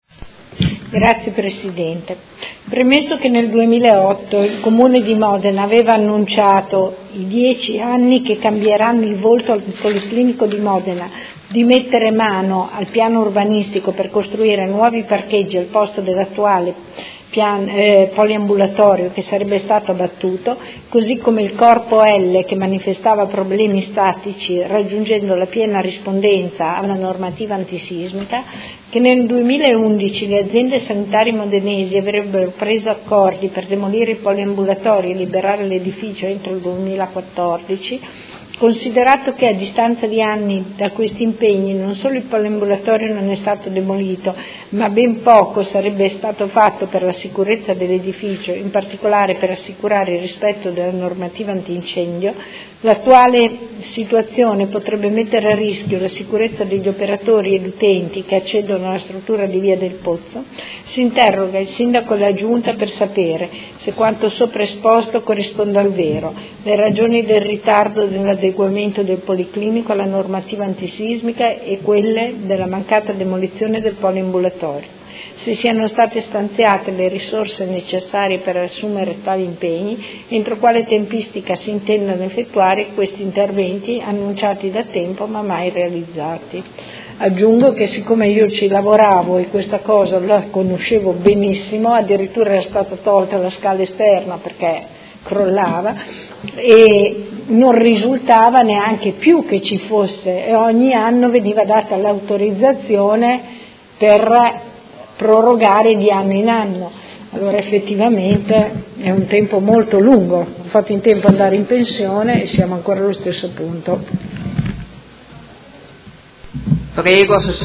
Seduta del 28/03/2019. Interrogazione della Consigliera Santoro (Lega Nord) avente per oggetto: Antisismica Poliambulatorio Policlinico